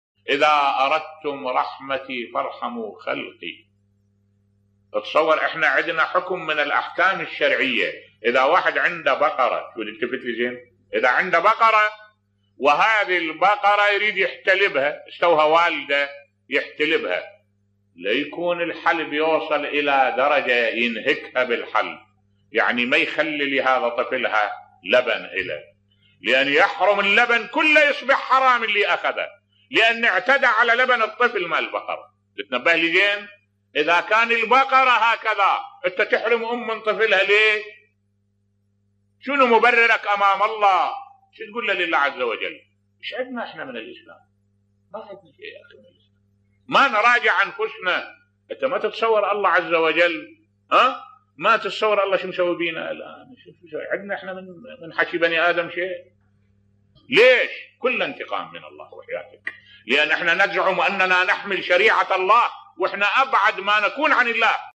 ملف صوتی الروح الإنسانية في الأحكام الاسلامية بصوت الشيخ الدكتور أحمد الوائلي